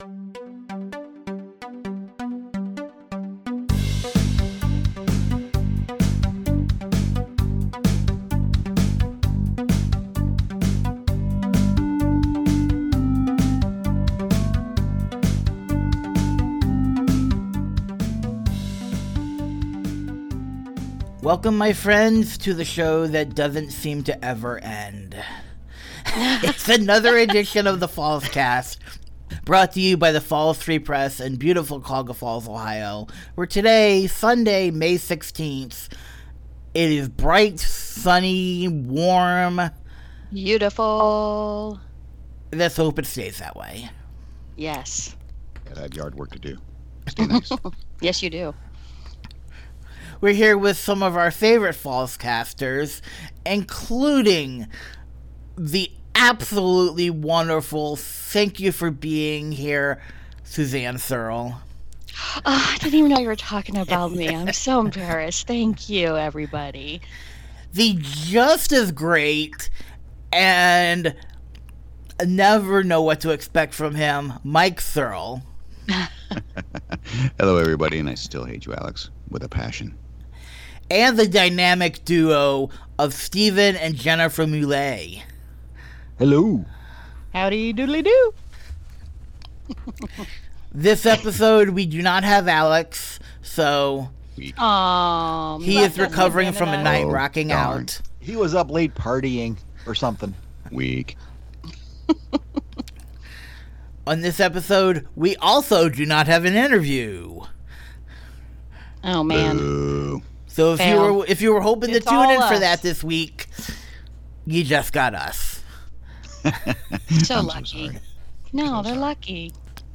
While we do not have an interview this week, we do discuss in our roundtable: